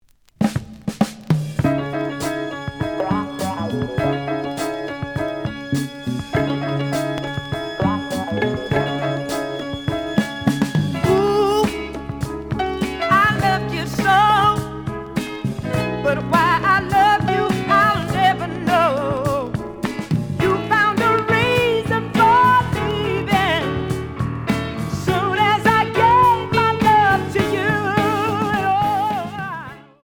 (Mono)
The audio sample is recorded from the actual item.
●Genre: Soul, 70's Soul